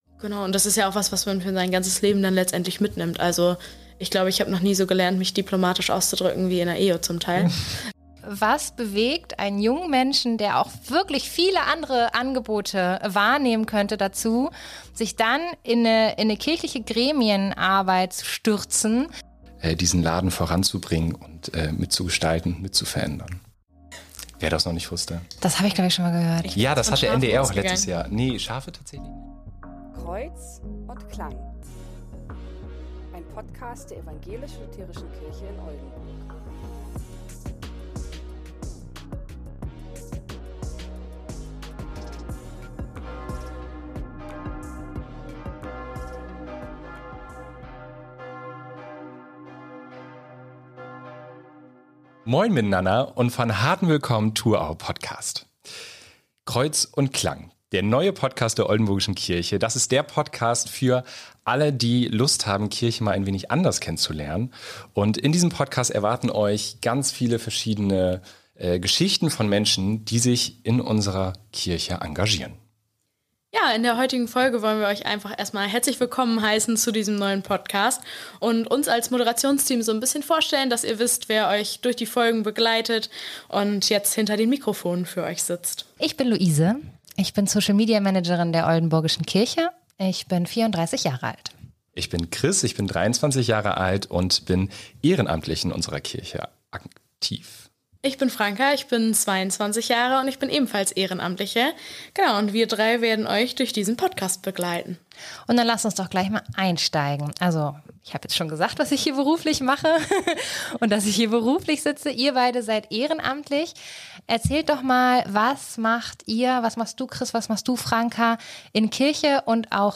Die drei Moderator*innen sprechen über die Motivation, einen Podcast zu starten, und diskutieren die Notwendigkeit, der Ev.-Luth. Kirche in Oldenburg eine moderne Stimme zu geben.